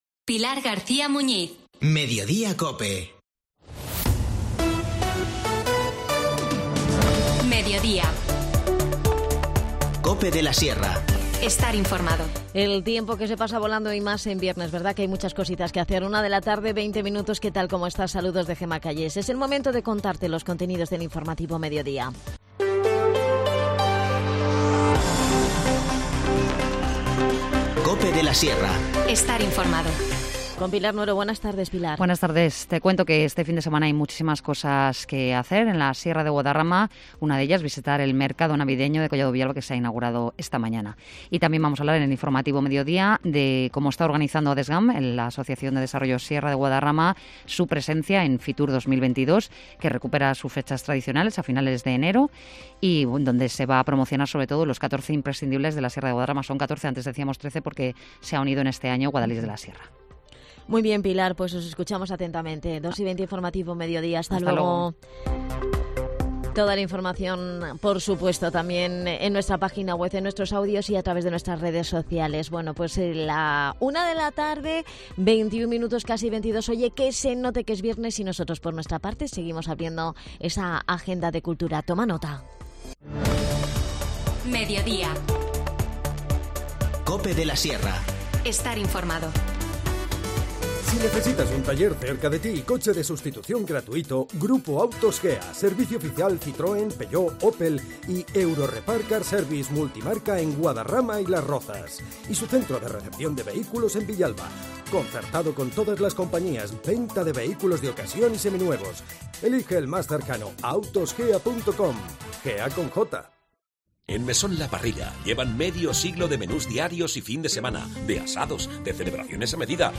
INFORMACIÓN LOCAL
Hablamos con su protagonista, Rafael Álvarez “El Brujo”